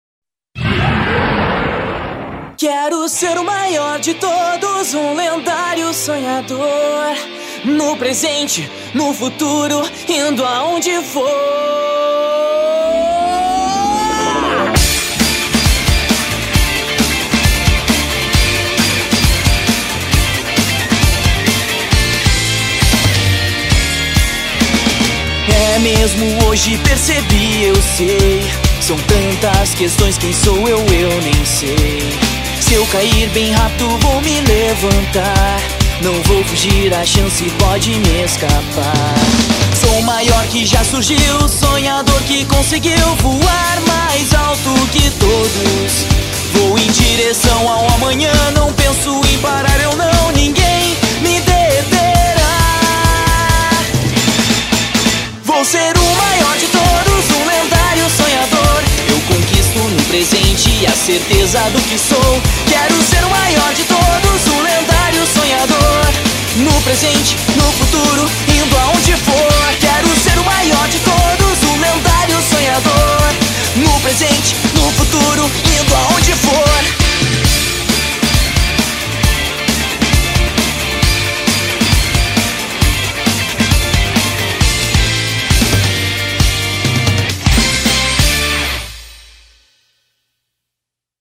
BPM134-134
Audio QualityMusic Cut